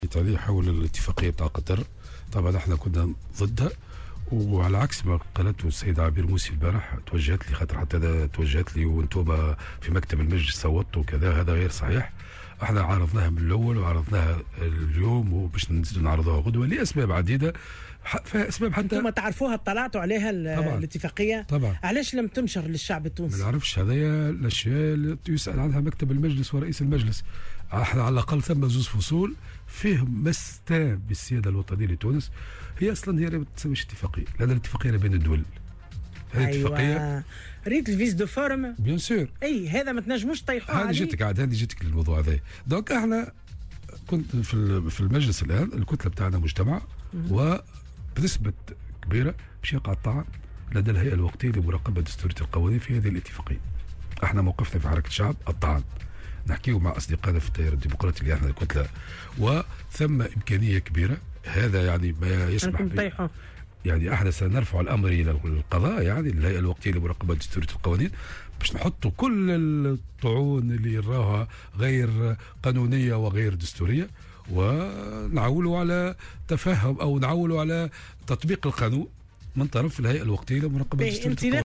وأضاف في مداخلة له اليوم في برنامج "بوليتيكا" أن كتلته بصدد الحديث مع نواب التيار الديمقراطي للتقدّم بطعن في الغرض.